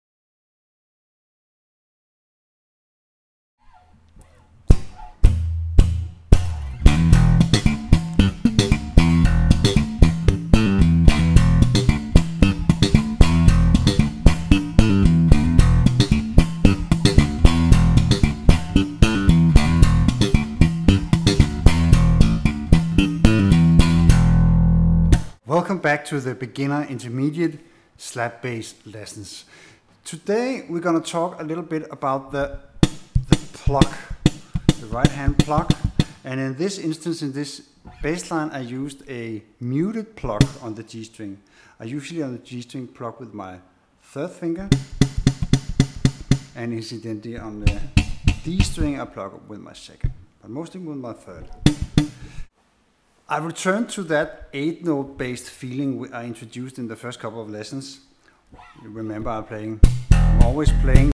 04 Slap bass 101 for novice slappers
04-Slap-bass-101Sample.mp3